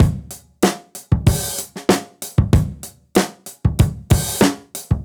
Index of /musicradar/dusty-funk-samples/Beats/95bpm